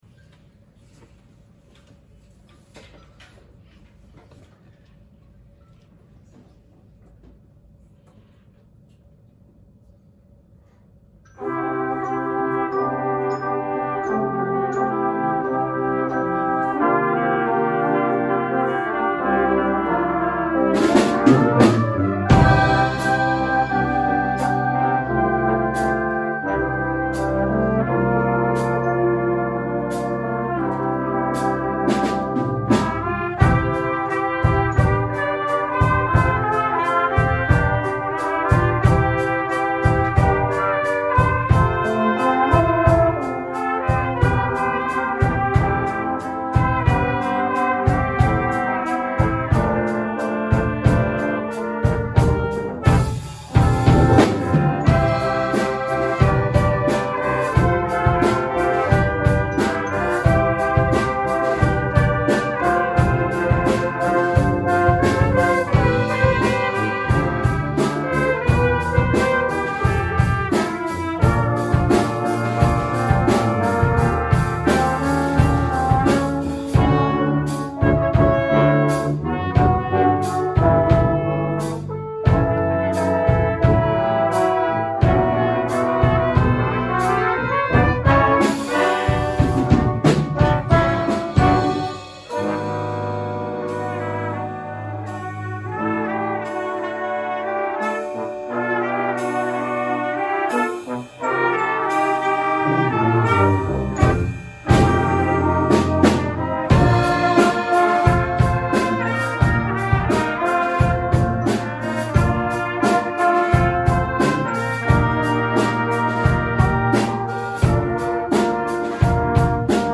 brass ensemble